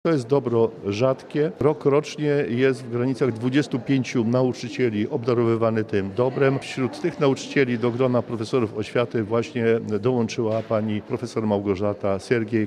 Warmińsko-mazurski kurator oświaty Krzysztof Marek Nowacki, potwierdza, że to najwyższy i rzadko przyznawany tytuł.